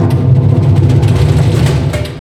24DR.BREAK.wav